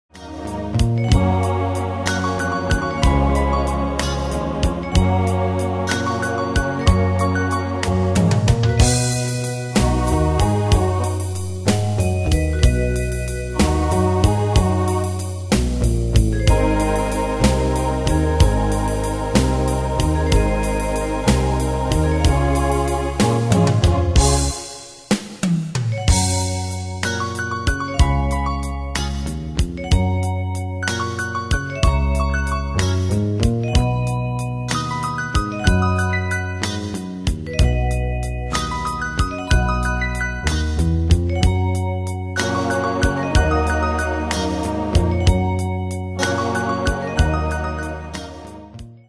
Style: Country Ane/Year: 1962 Tempo: 125 Durée/Time: 3.04
Danse/Dance: Ballade Cat Id.
Pro Backing Tracks